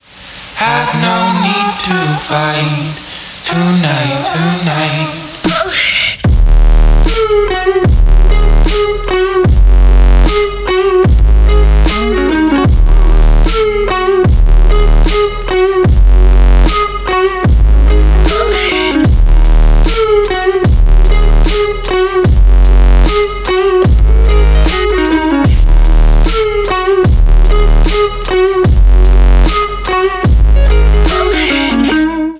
мощные басы
чувственные
электрогитара
alternative
Electronic Rock
вздохи